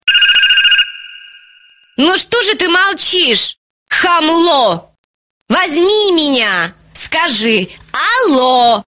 Прикольные звонки